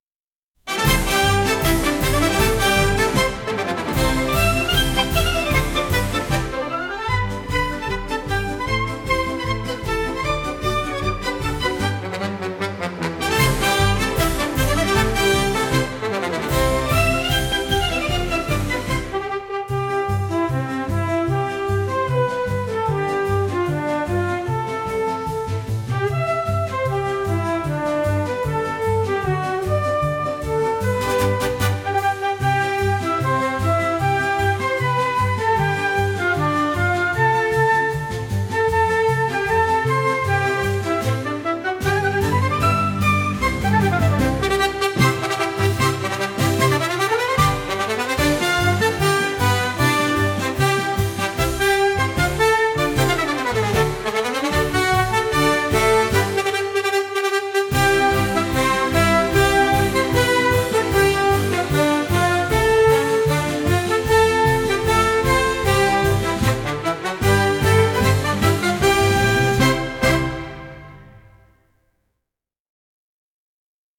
やさしいピアノとバイオリンの曲です。